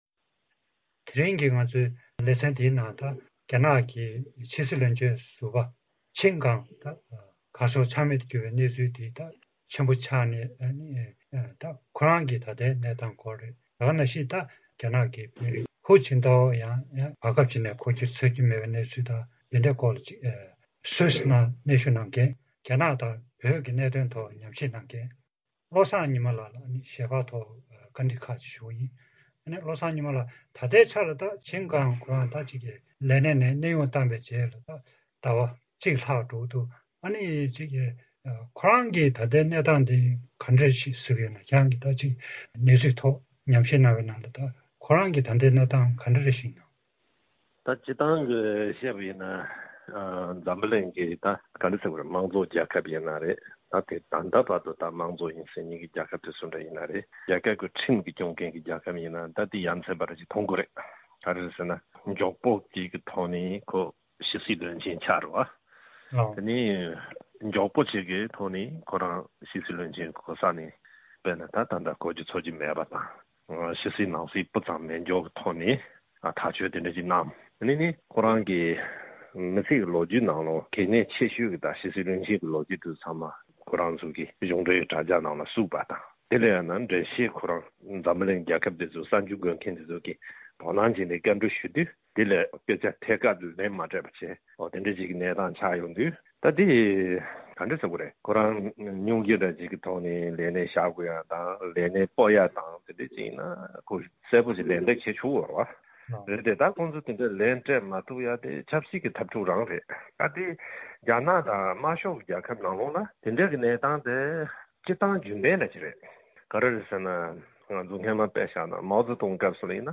གནས་འདྲི་ཞུས་པ